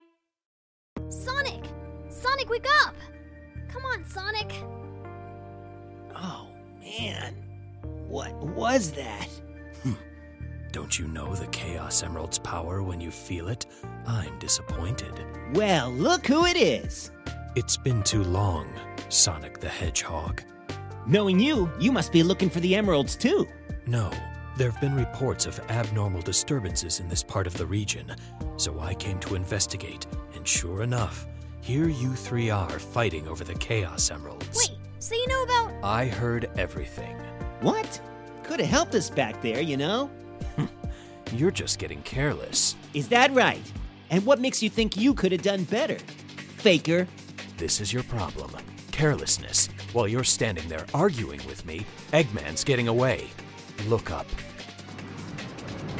while i'm on a Sonic day, 2000s fans should have Sonic & Tails R as a must-listen. it's a ten-part audio drama made by fans, but the casting is off the charts, with a lot of big names showing up out of nowhere.
>Ryan Drummond as Sonic for the first time since SA2
>David Humphrey as Knuckles, the original voice of Shadow in SA2
>Mike Pollock as always playing Eggman